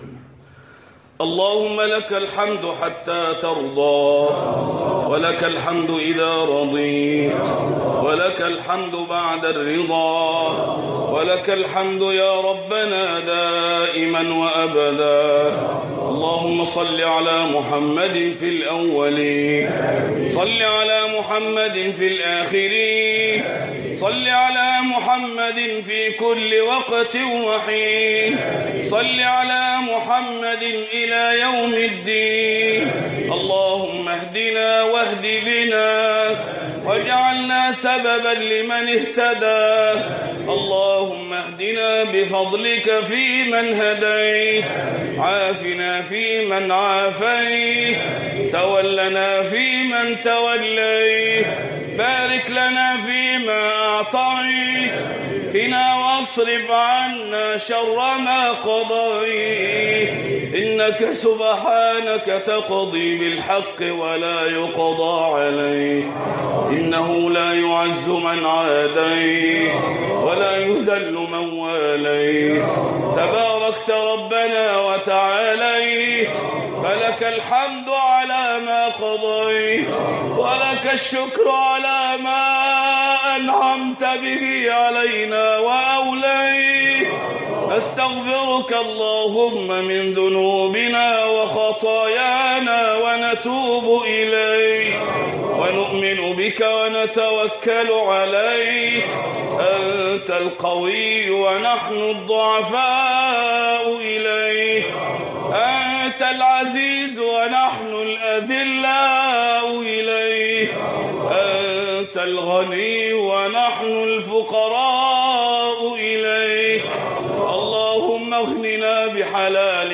مقطع صوتي يضم دعاء خاشع ومؤثر، خُصص للدعاء في ليلة القدر المباركة.